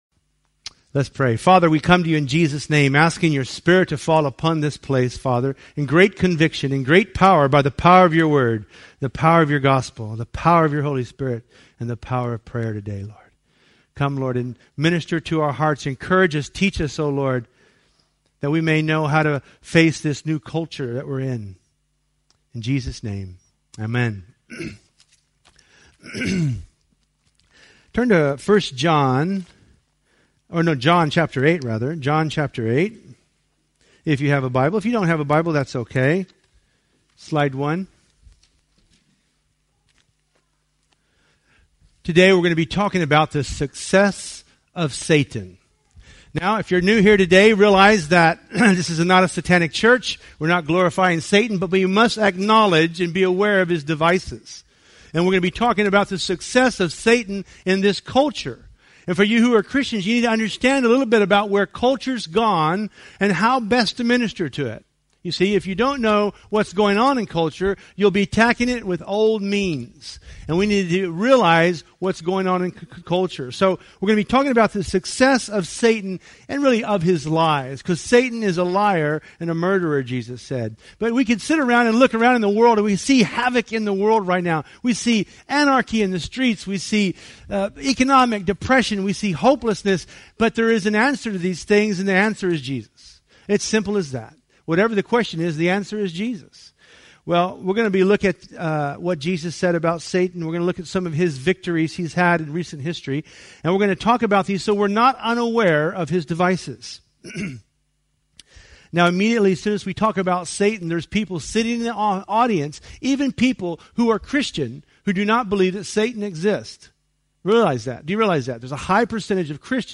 Preached at a Taste & See Evangelistic Event